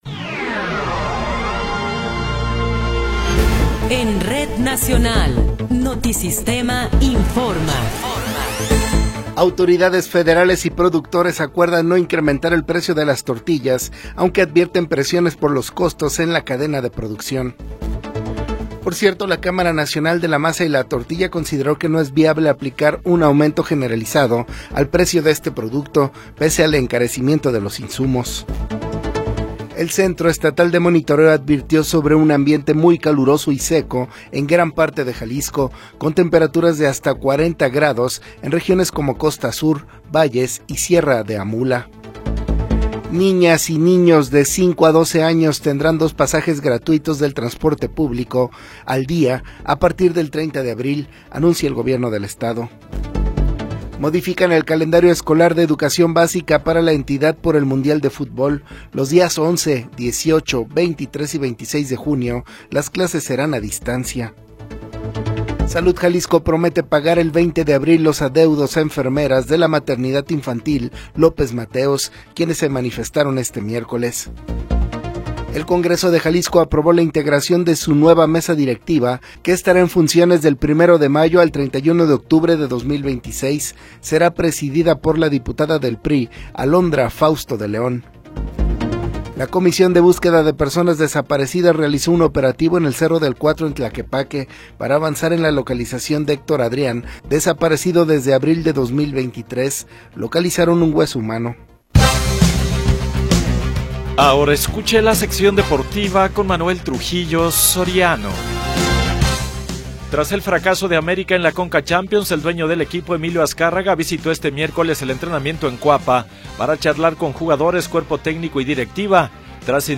Noticiero 21 hrs. – 15 de Abril de 2026